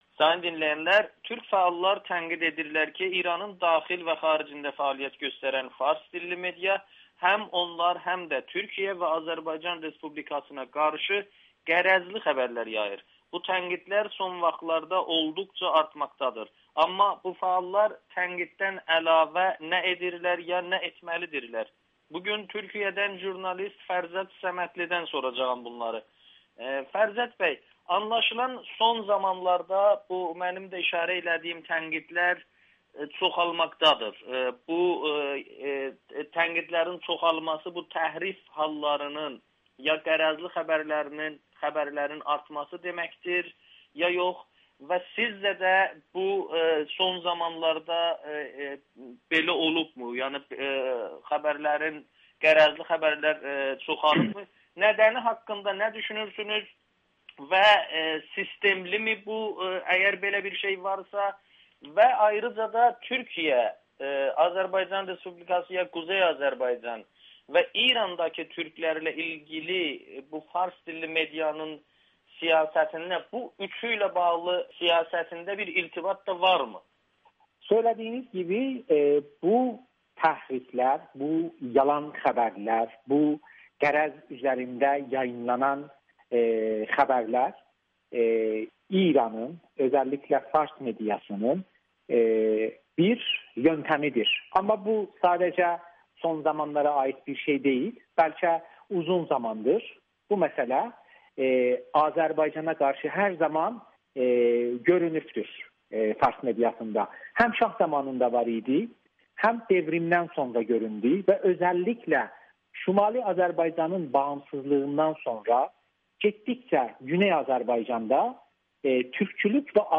İran mediası türklərlə bağlı xəbərləri təhrif edir [Audio-Müsahibə]